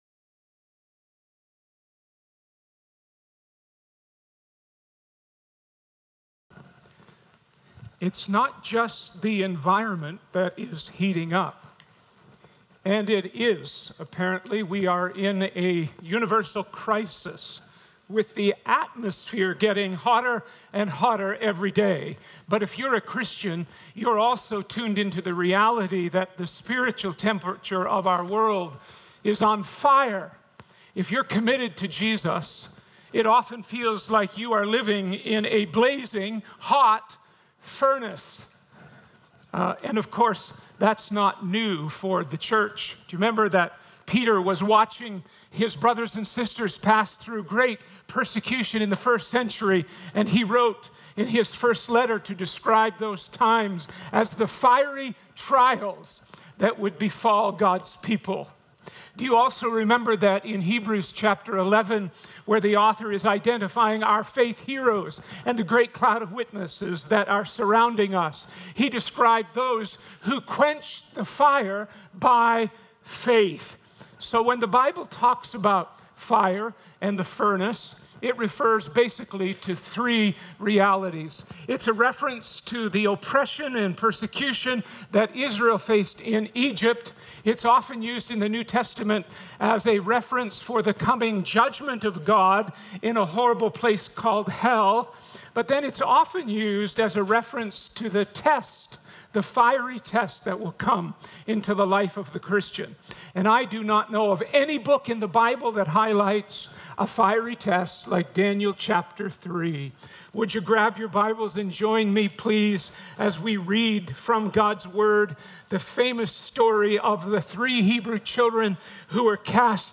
The sermon last Sunday, November 16, was truly uplifting and encouraging.